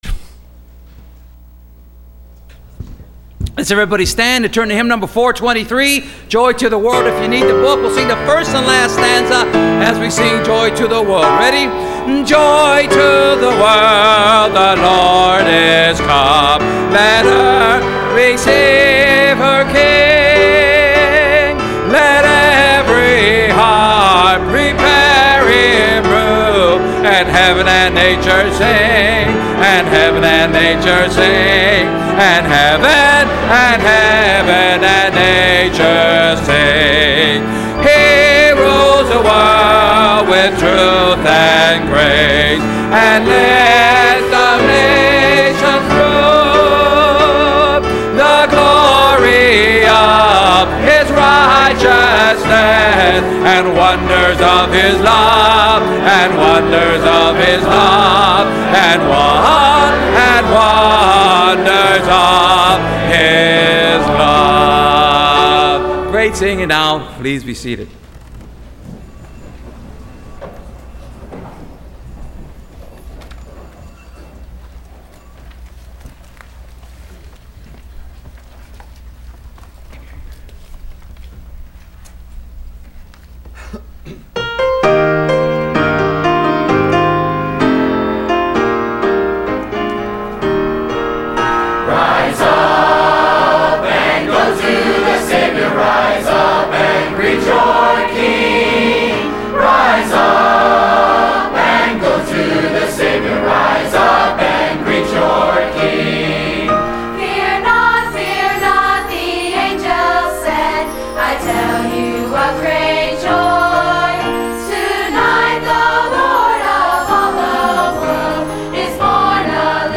College Christmas Program – Landmark Baptist Church
Service Type: Wednesday College